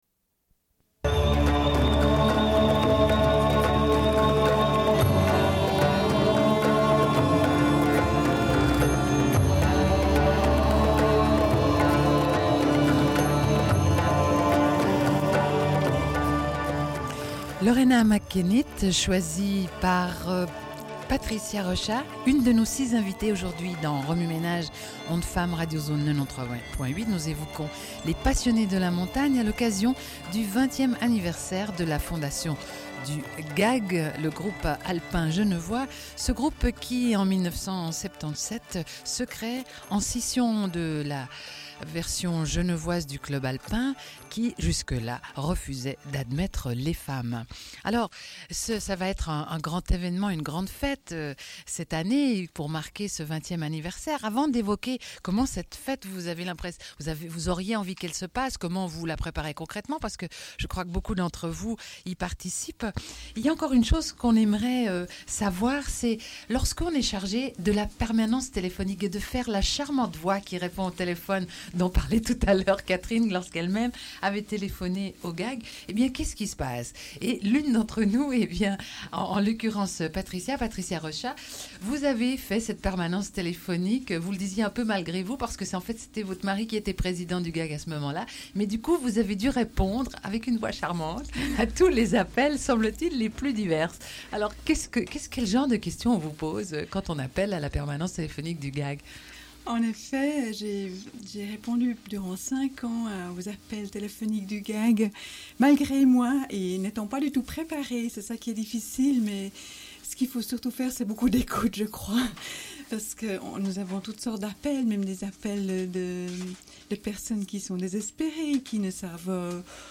Suite de l'émission : à l'occasion des 20 ans du Groupe alpin genevois (GAG), avec six femmes en direct.
Une cassette audio, face A